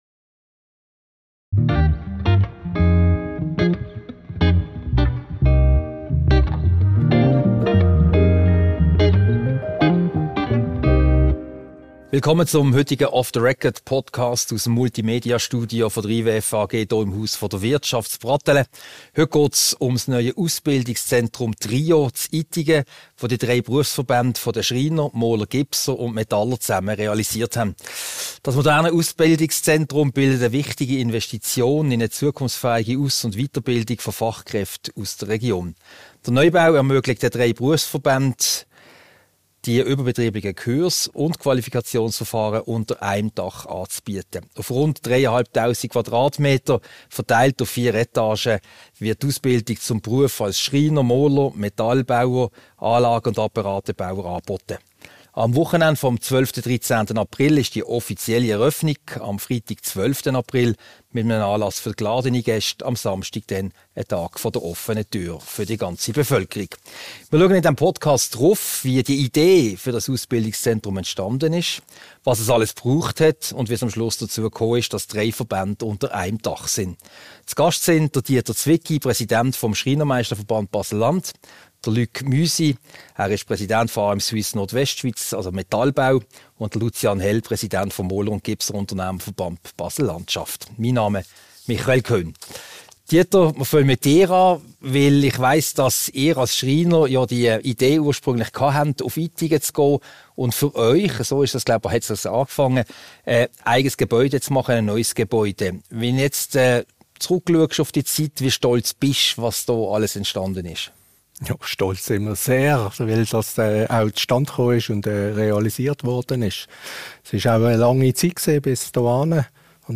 Ein Gespräch über TRIO in Itingen, das neue, gemeinsame Ausbildungszentrum des Schreinermeisterverbandes Baselland, des Maler- & Gipserunternehmer-Verbandes Baselland sowie der Metallbauer AM Suisse Nordwest. Dort werden die überbetrieblichen Kurse (ÜK) und die Qualifikationsverfahren für vier Handwerksberufe (Schreiner, Maler, Metallbauer sowie Anlage- und Apparatebauer) angeboten und durchgeführt.